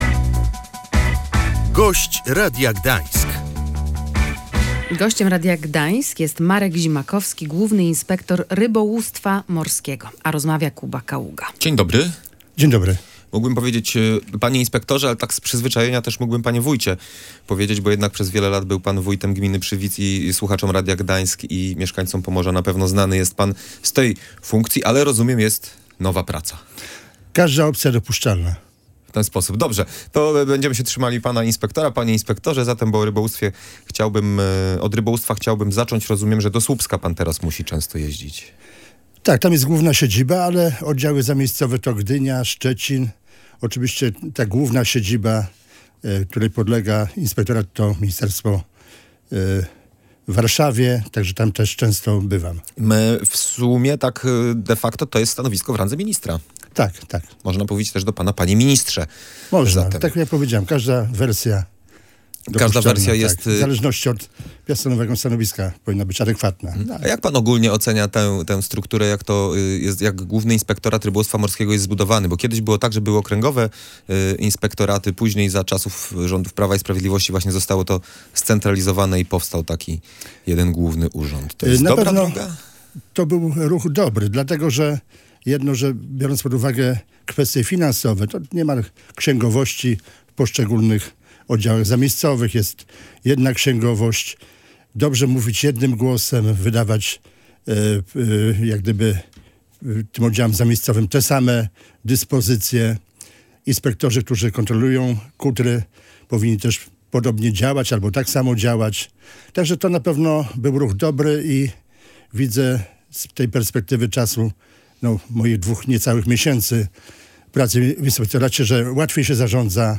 Rozmowa z Markiem Zimakowskim, Głównym Inspektorem Rybołówstwa Morskiego.